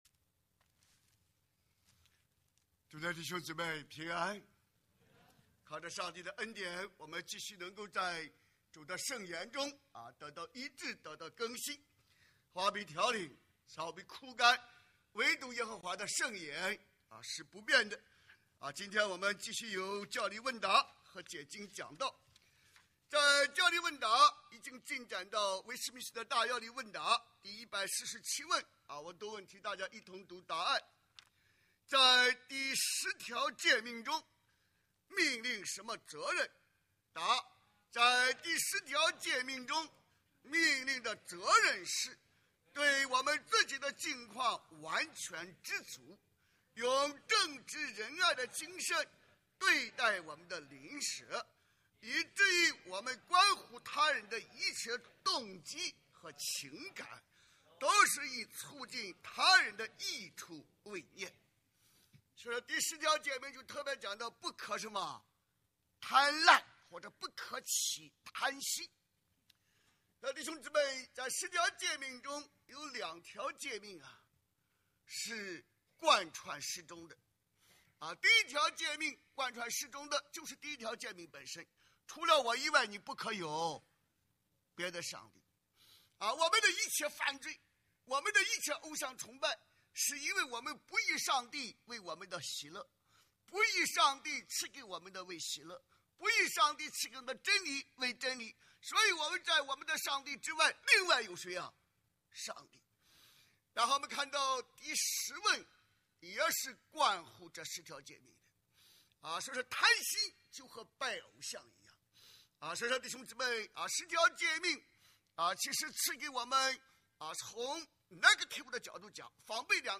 主日證道